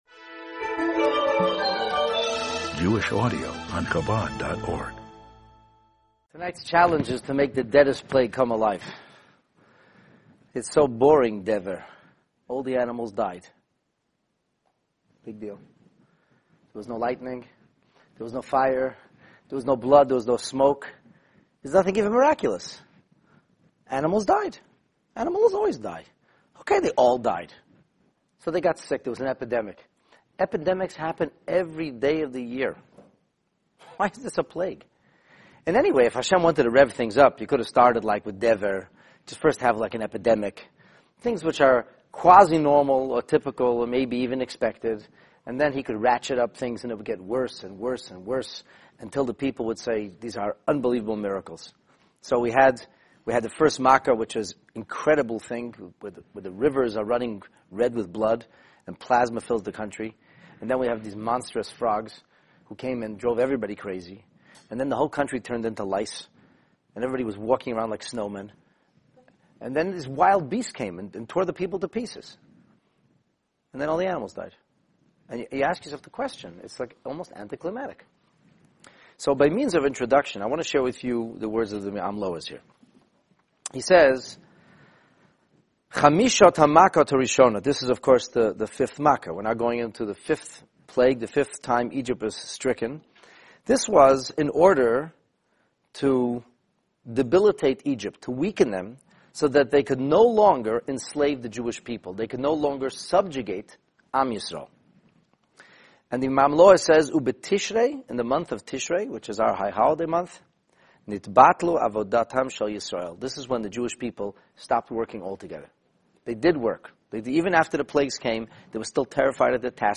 The Ten Plagues 5 Pestilence (Dever) This class makes the seemingly deadest of the Proverbial Plagues come alive with colorful insights and sensational secrets revealed. Discover what really happened to the ancient world's superpower when they were suddenly plagued by pestilence – the fatal epidemic striking all Egyptian livestock – as this penetrating analysis allows the real story to emerge!